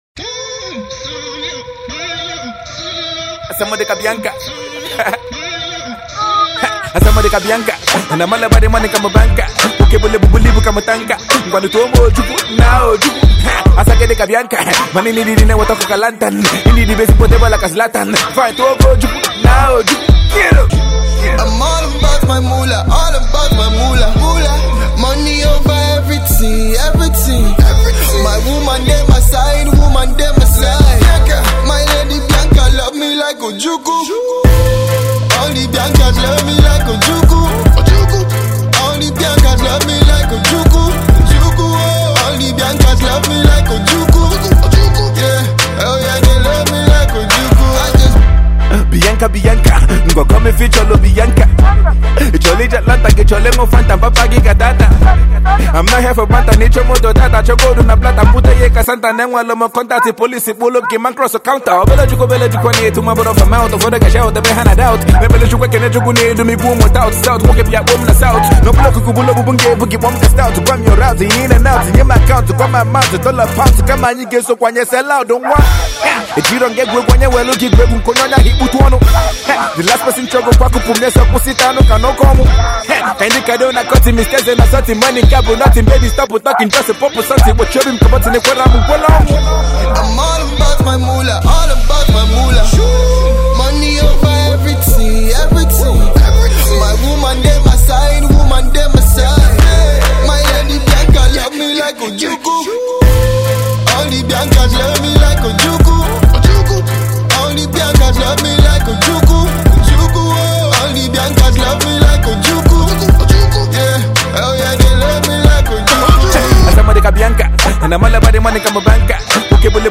Igbo rapper